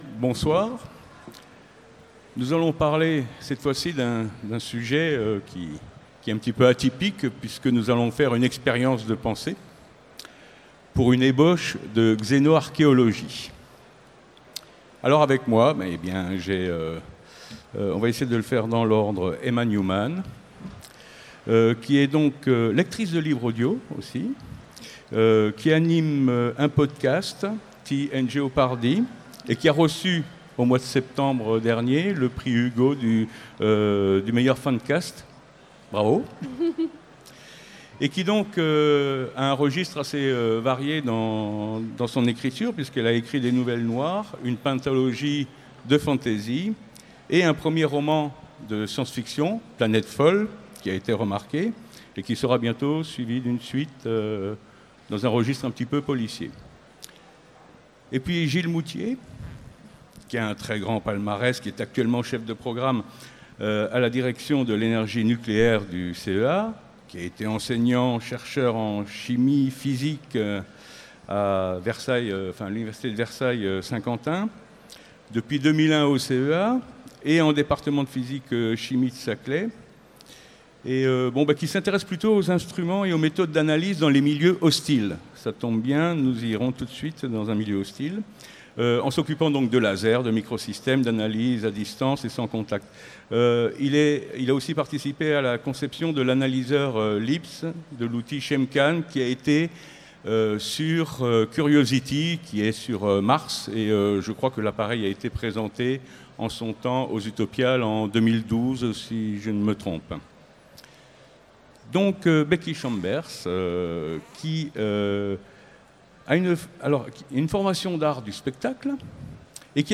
Utopiales 2017 : Conférence Pour une ébauche de xénoarchéologie